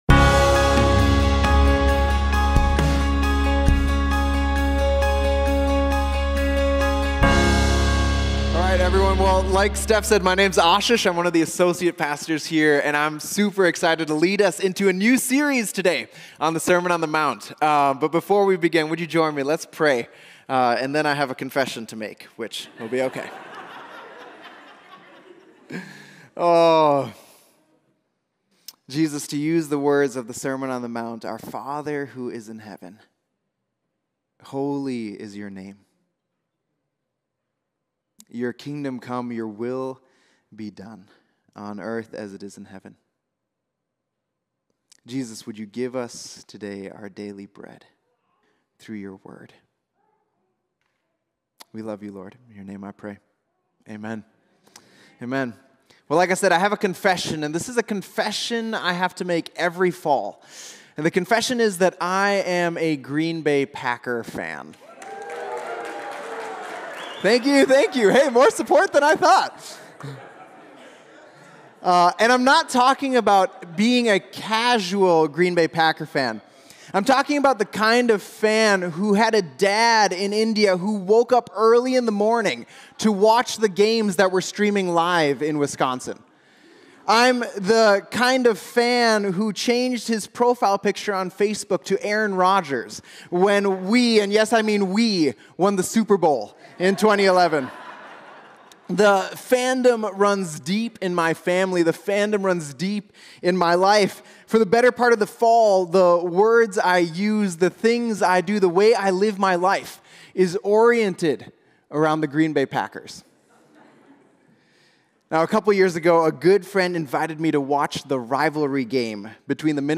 Mill City Church Sermons Sermon on the Mount: Starting with Prayer Sep 17 2024 | 00:36:39 1x 00:00 / 00:36:39 Subscribe Share RSS Feed Share Link Embed